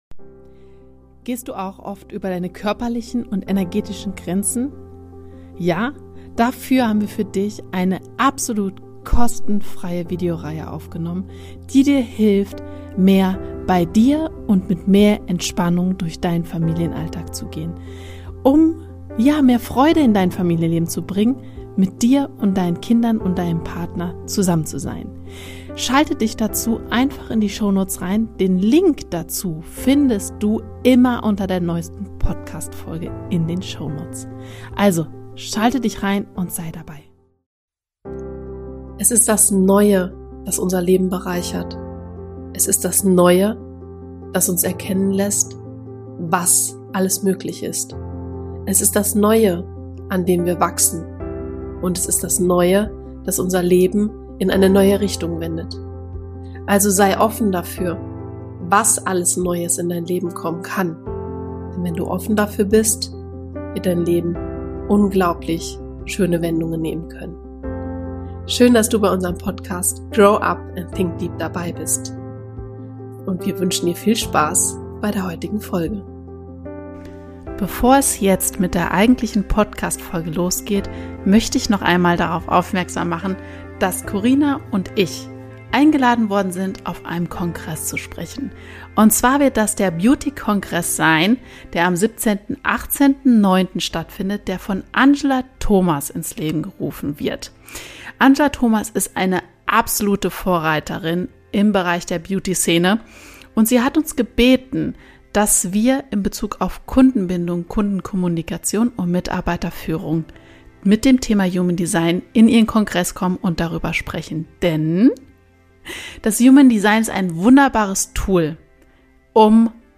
In dieser Solofolge berichte ich